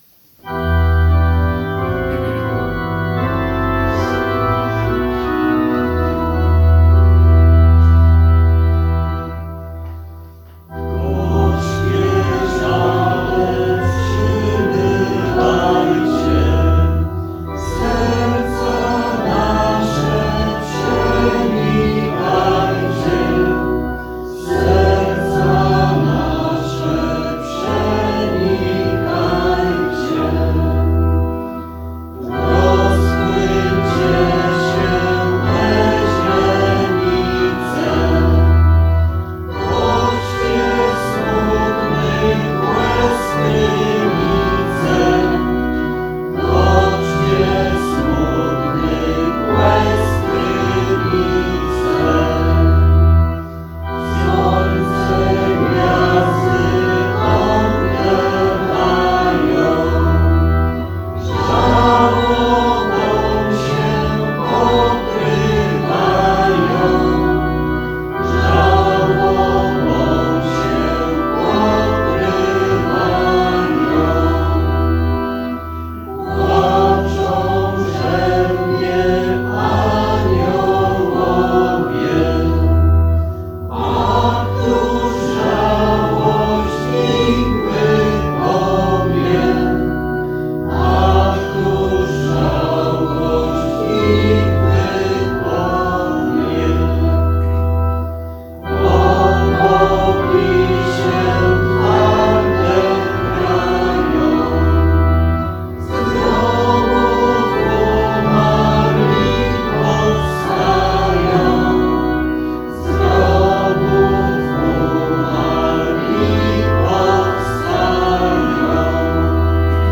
Gorzkie Żale, cz. 3 Oratorium Rzymskokatolickie św. Józefa Wrocław, ul. Przedmiejska 6-10 Niedziela Palmowa, 13.04.2025 Organy